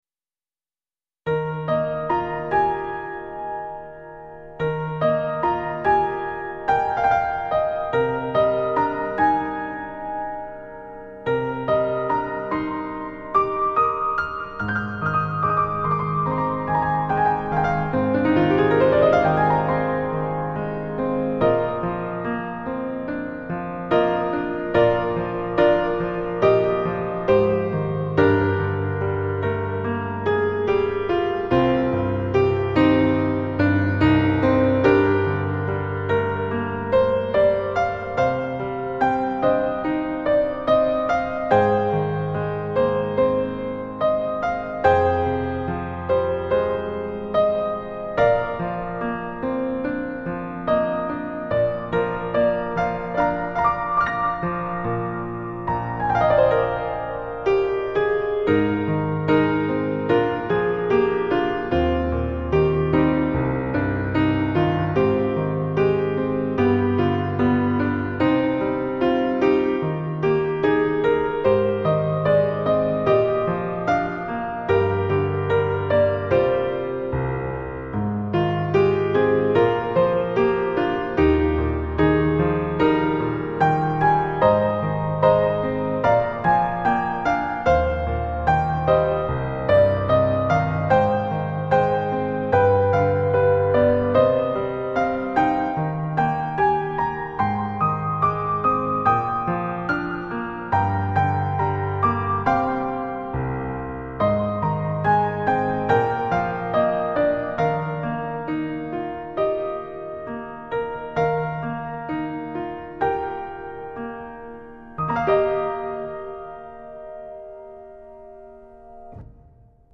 ピアノのソロのみで、白黒のスケッチのようなものですが。